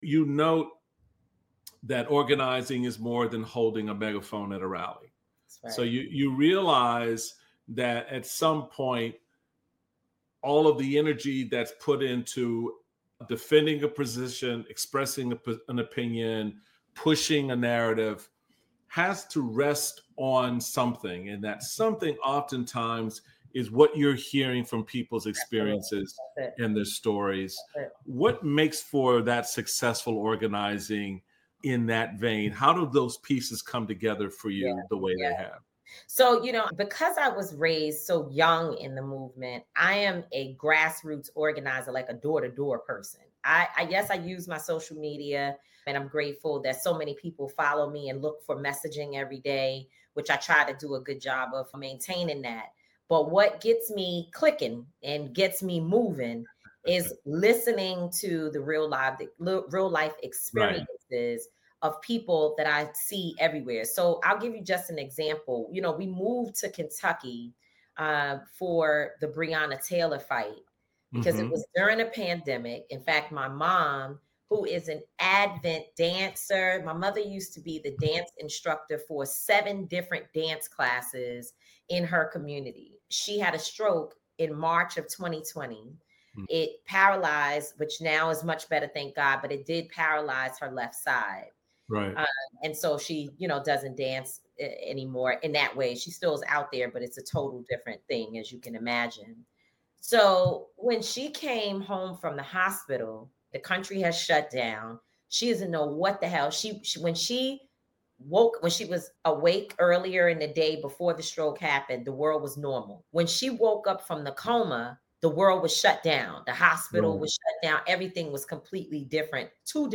Michael Steele speaks with Tamika D. Mallory, social justice leader and movement strategist who led the historic 2017 Women’s March.